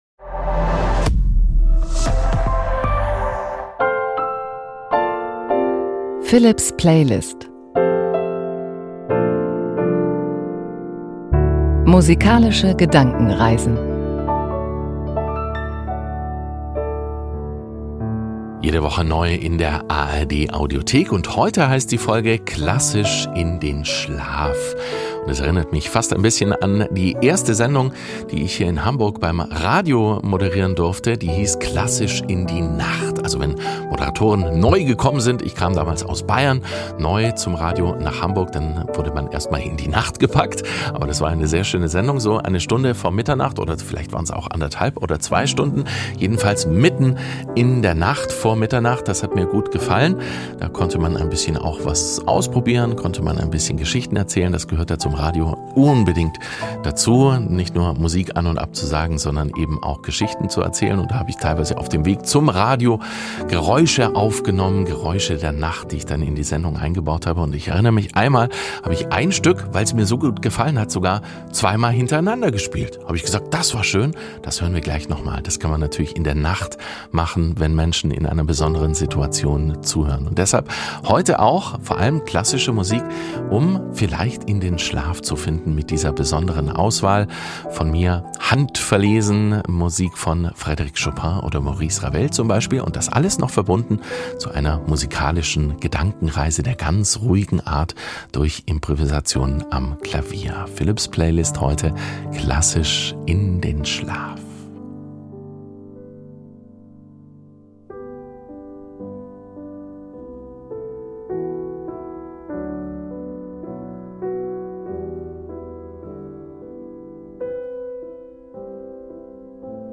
Ruhige Musik
ein leiser Strom aus Klang.